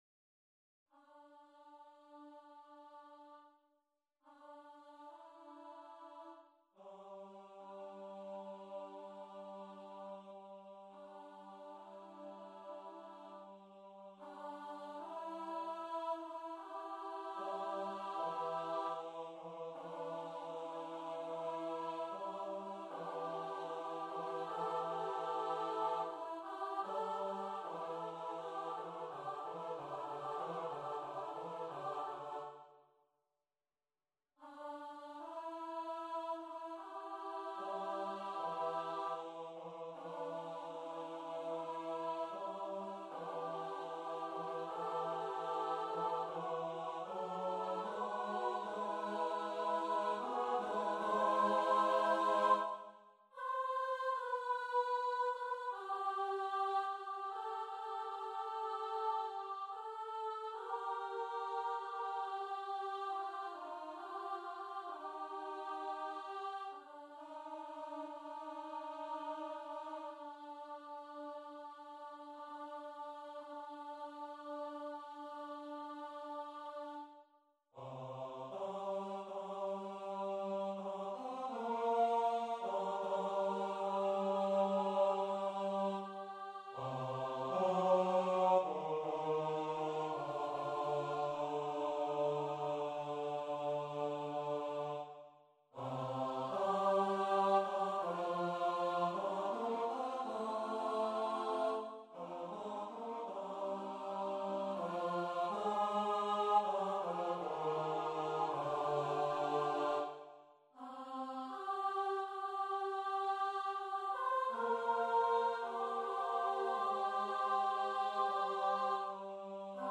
Emily Dickinson Number of voices: 3vv Voicing: SAB Genre: Secular, Madrigal
Language: English Instruments: A cappella